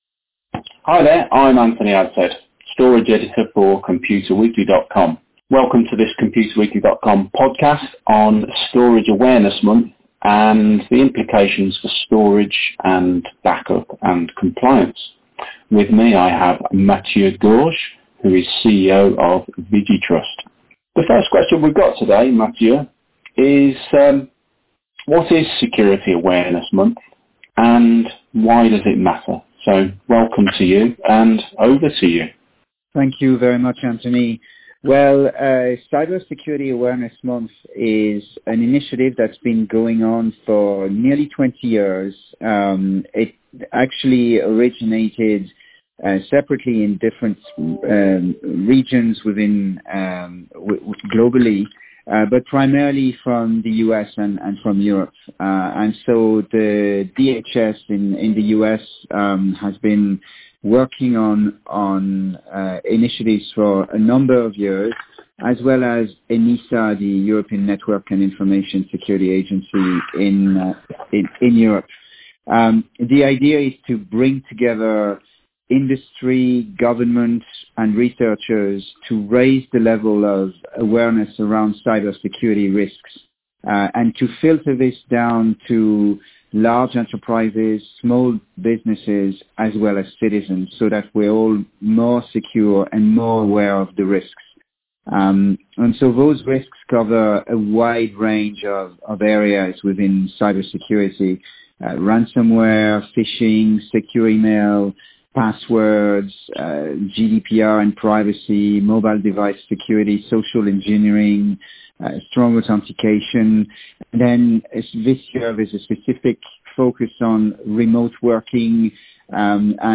We talk with him about how Cybersecurity Awareness Month can be used to refocus organisations and employees on cyber security fundamentals and compliance, especially in the light of the huge changes to working practices brought about by the Covid-19 coronavirus.